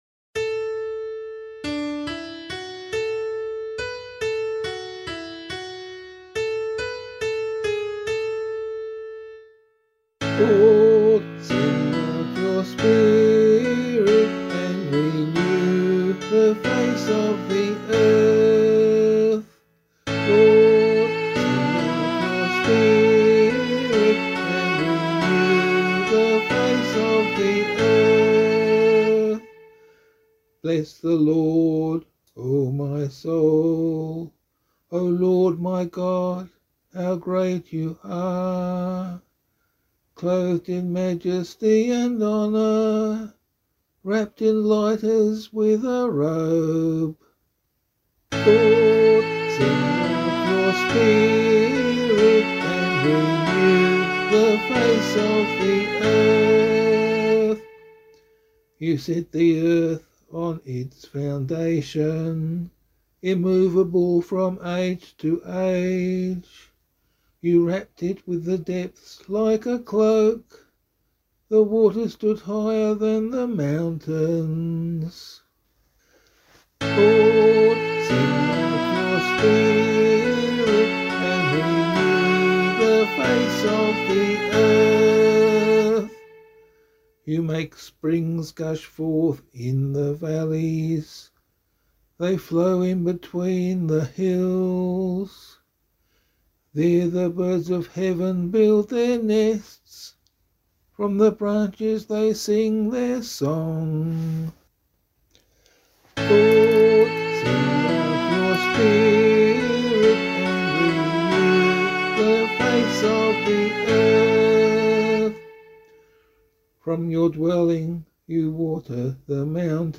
022 Easter Vigil Psalm 1A [Abbey - LiturgyShare + Meinrad 8] - vocal.mp3